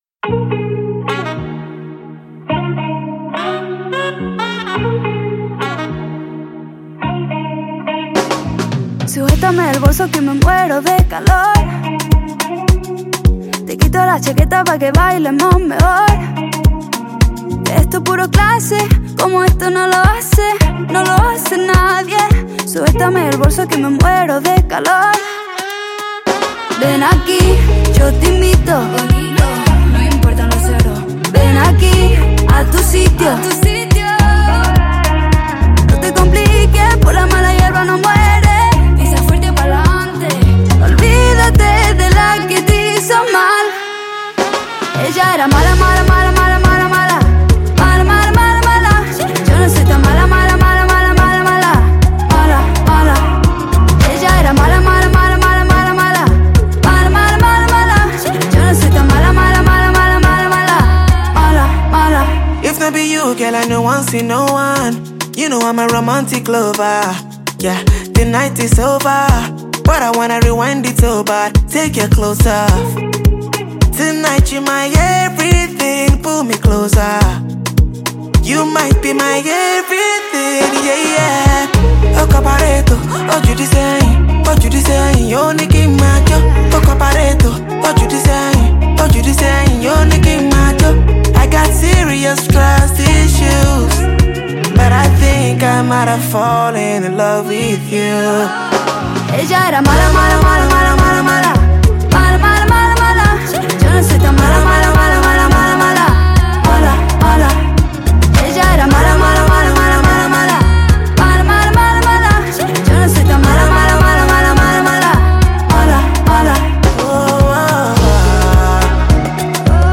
Norwegian pop and R&B singer
African Music